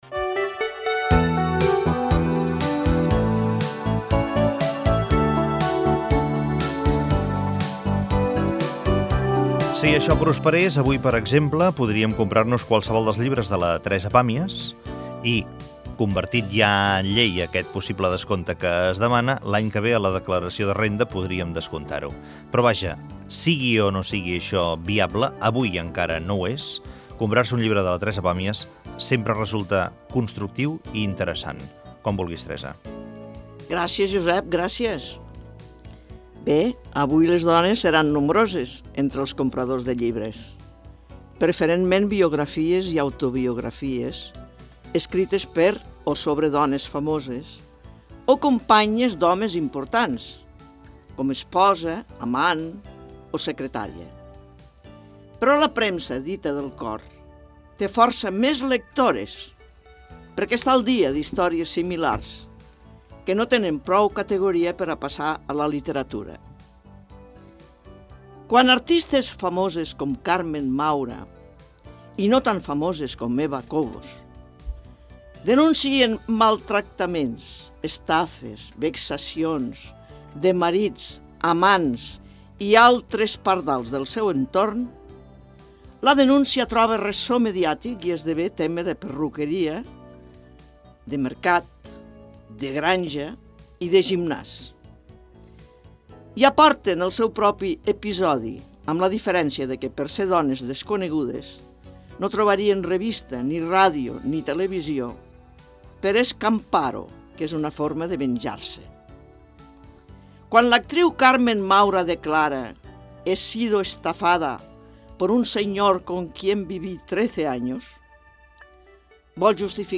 Secció de l'escriptora Teresa Pàmies. Parla de les dones i les compres de llibres.
Info-entreteniment